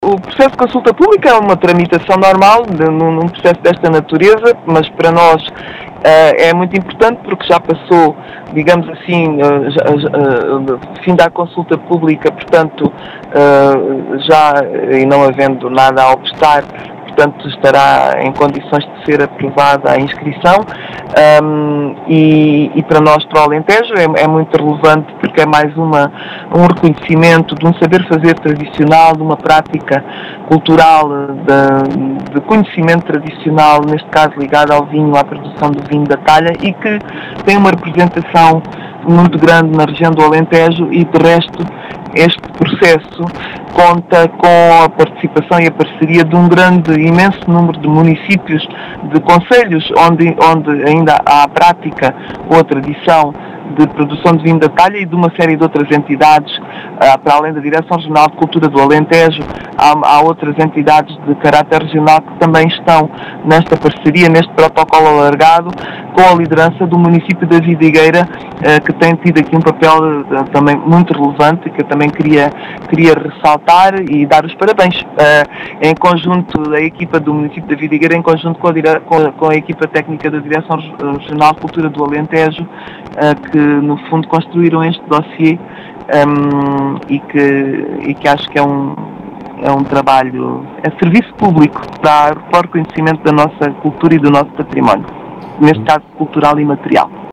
As explicações são de Ana Paula Amendoeira, directora regional de cultura do Alentejo, diz ser “mais um reconhecimento do saber fazer” no Alentejo.